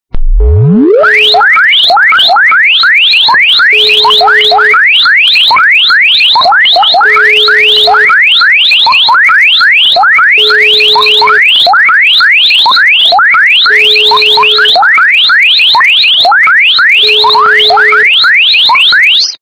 При прослушивании для СМС - Irritone качество понижено и присутствуют гудки.
Звук для СМС - Irritone